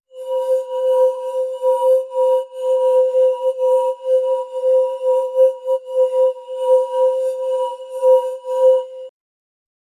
ai voice mode turn on futuristic sound. Very futuristic. Glass sound.
ai-voice-mode-turn-on-wk6kofap.wav